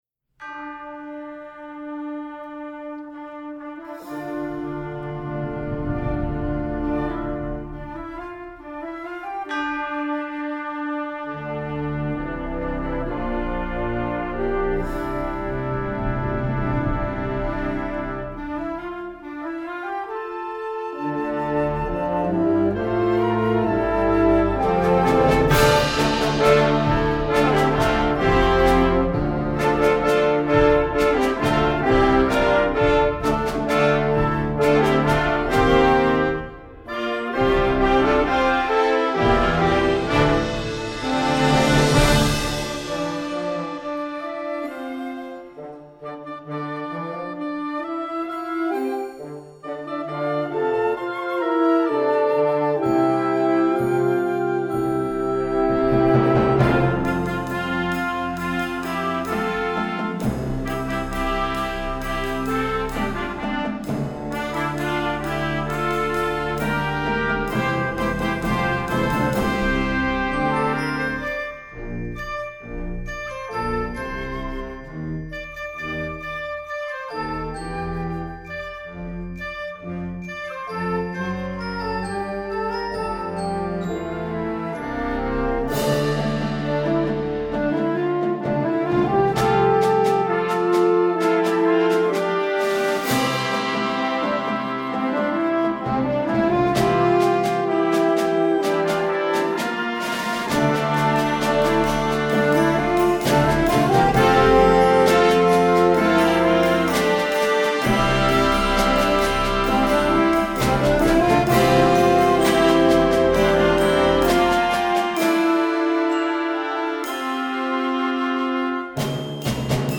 Instrumentation: concert band (full score)